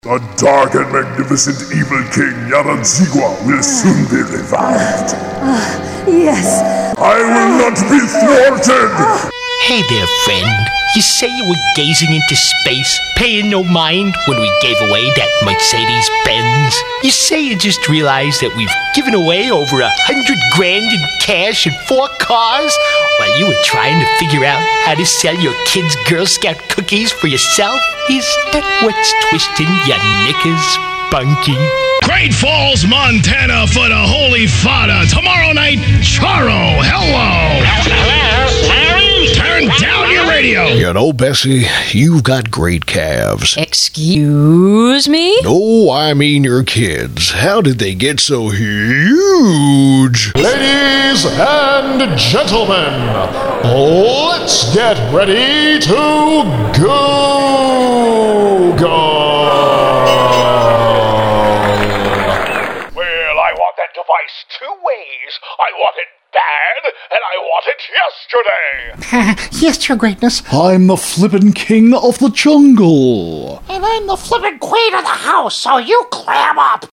VOICEOVER DEMOS
Character Demo
Broadcast-quality home studio with digital delivery; ISDN with 24-hour notice.